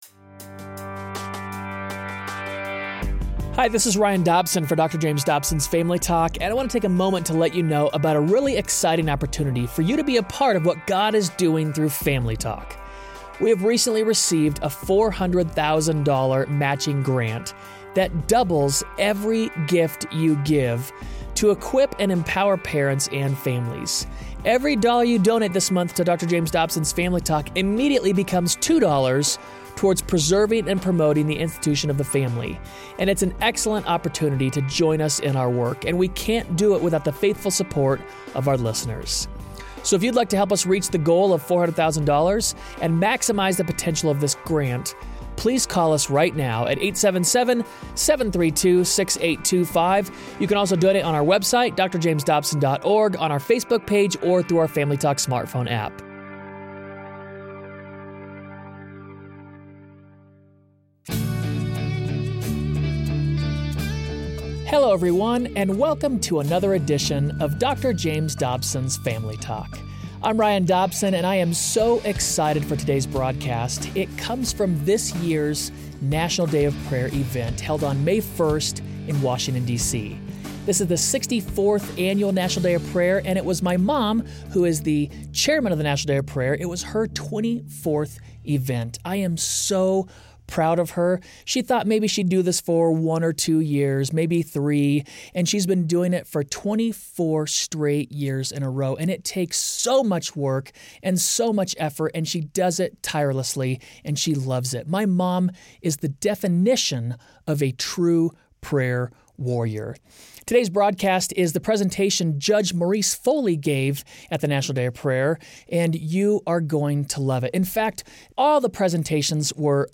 Judge Maurice Foley spoke at the 2014 National Day of Prayer event on the virtue of humility.